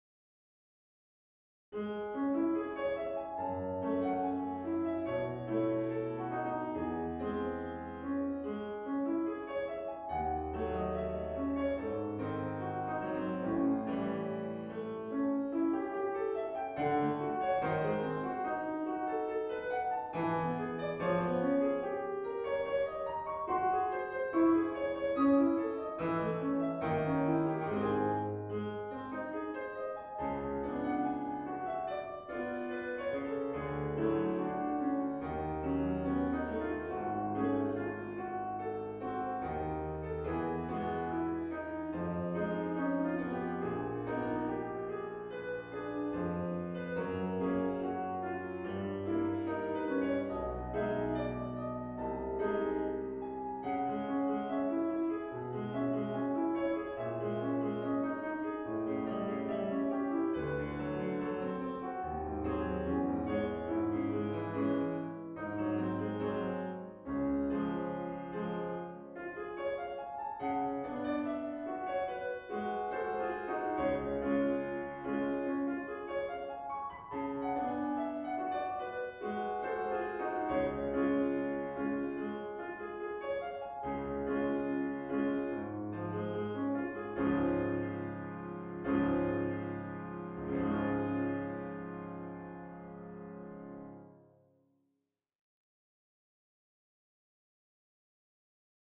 この中の第５曲は1901年にパリ音楽院の卒業試験のために作られた作品で、演奏技術は平易なものですが、巧みな転調を繰り返す味わい深い作品です。
調性は開始部分から近親調の間を揺れ動いて、要所に置かれた終止定型がなければ、浮動調性のような様式ですね。